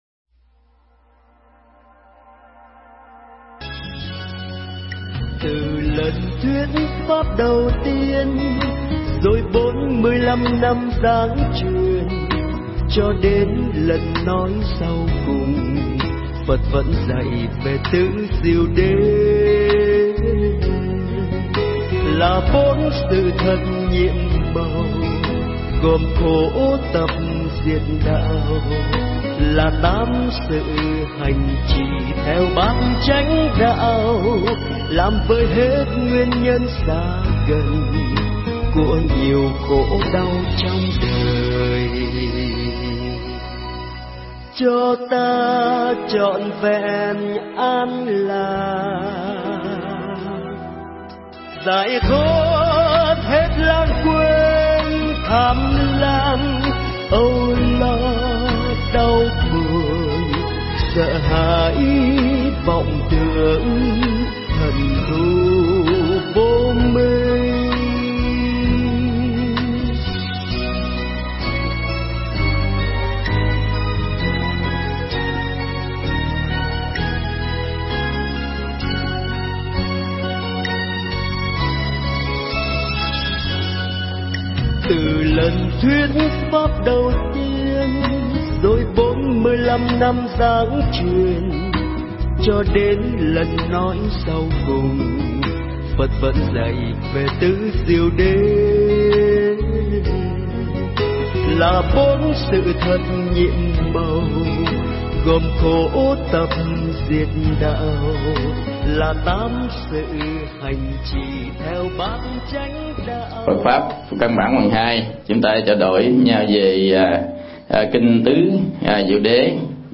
Thuyết Giảng Phật Pháp căn bản